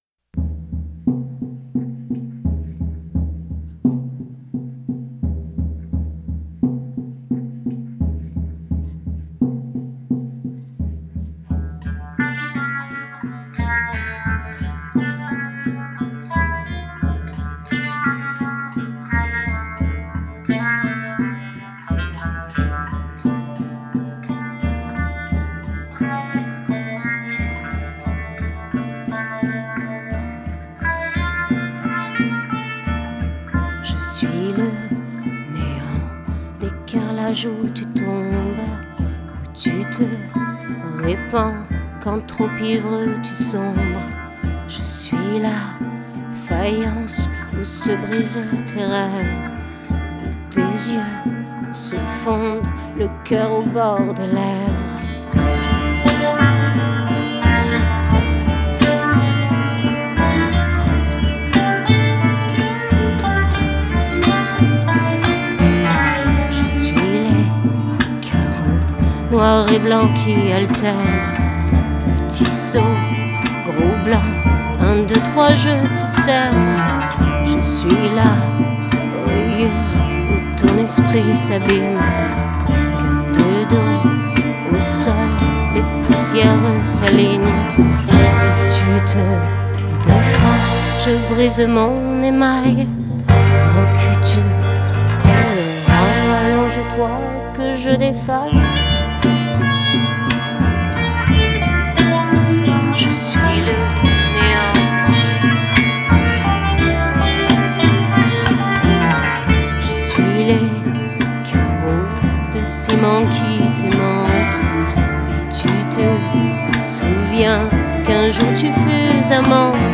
guitares/chant
batterie
basse
Enregistré rue de la Fidélité, Paris 10,